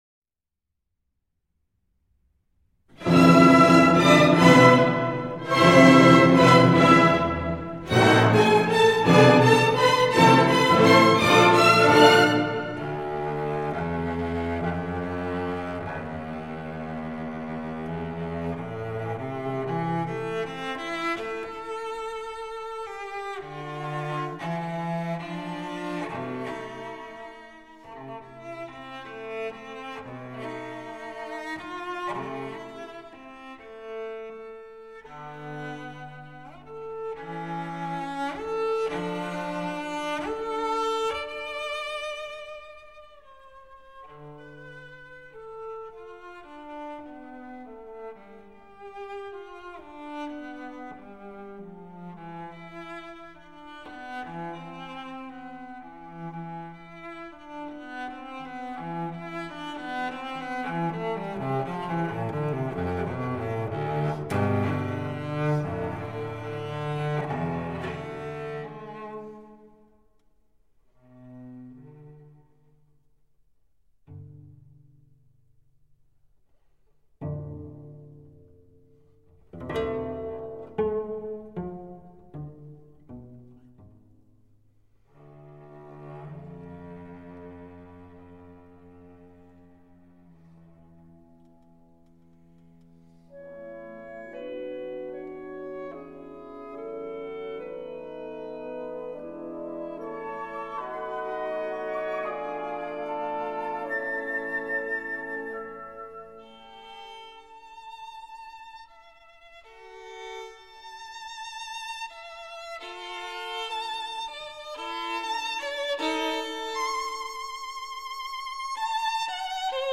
Concerto
Concerto for Violin, Violoncello and Orchestra in A minor
Allegro